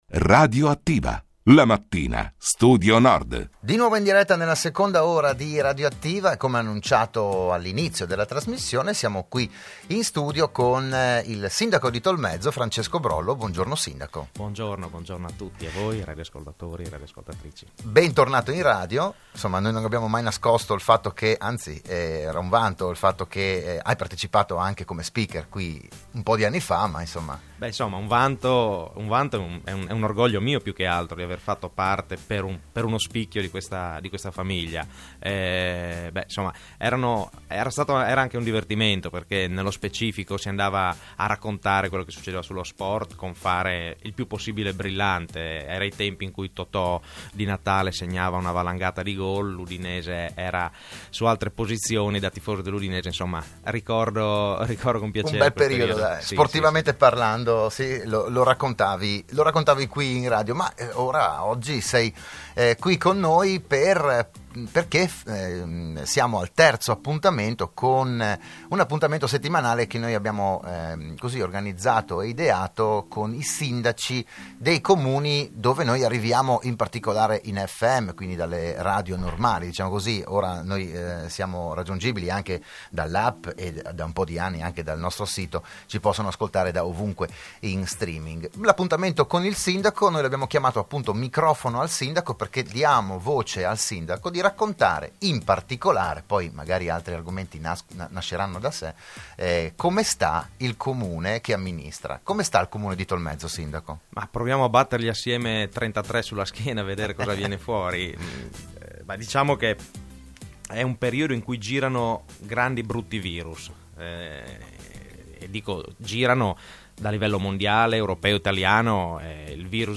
Periodicamente sarà ospite negli studi di RSN un sindaco del territorio per parlare della sua comunità, delle prospettive, degli eventi e quant’altro.